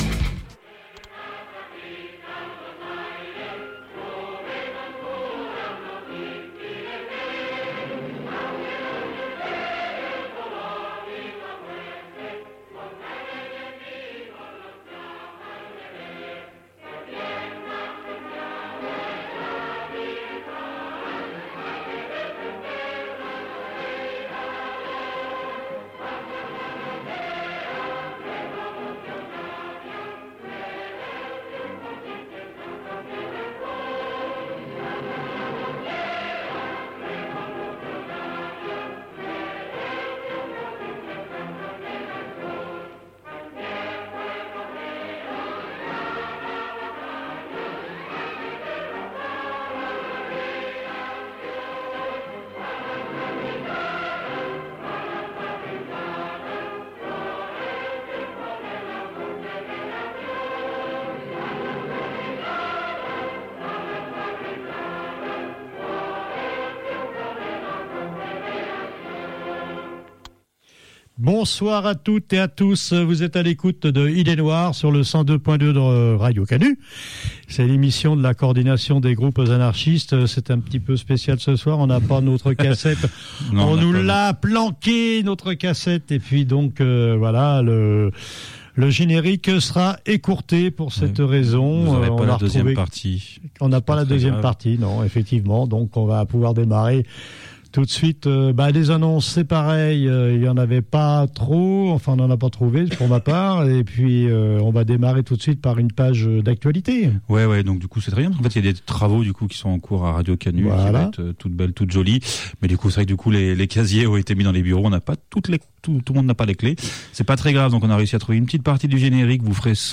Salut, Au sommaire de cette émission : Solidarité Riseup Projet de réforme constitutionnel Point sur les vacataires à Lyon 2 Plaintes contre répressions policières Communiqué de migrant-es de Paris Foyers ADOMA Pause musicale : Aux armes citoyens Luttes sociales Pause musicale : Demain c’est Noël!